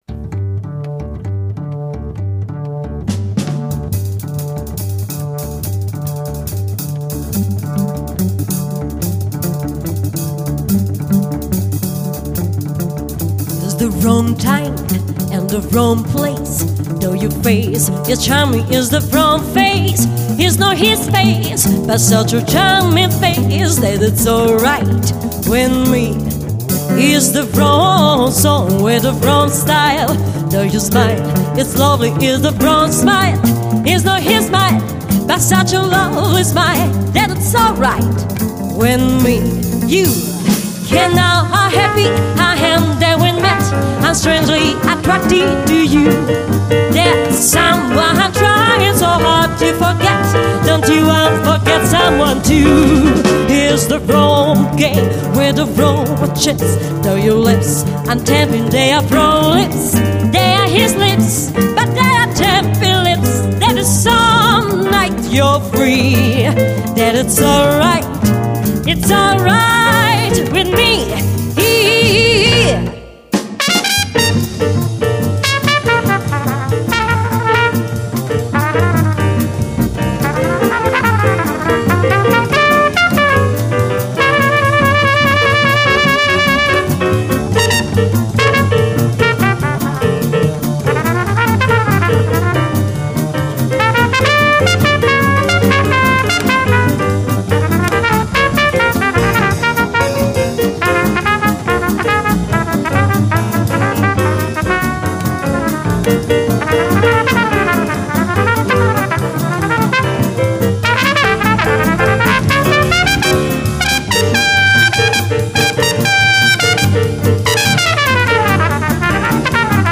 voce
tromba
chitarra
piano
contrabbasso
batteria